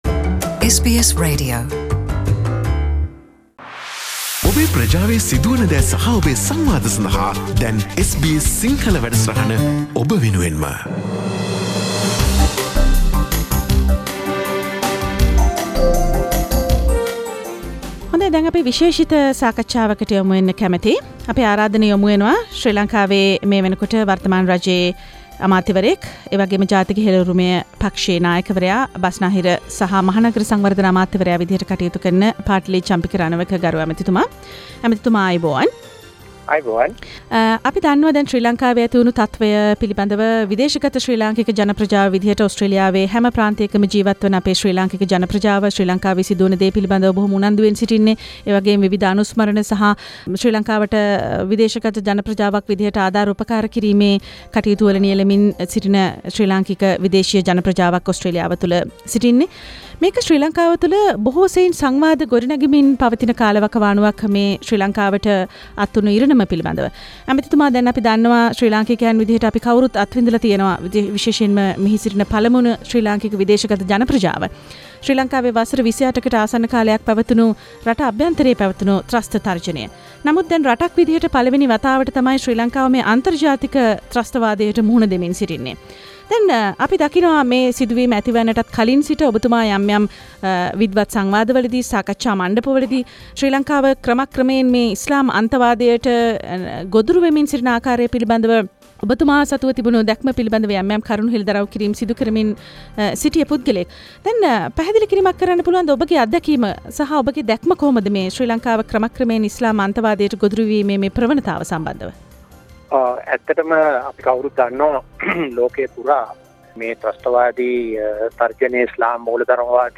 Minister for the Mega polis and Western development Ptali Champika Rakawaka speaks to the SBS Sinhala Radio about the arising Islam radicalisation and how does it affect to the future governing in Sri Lanka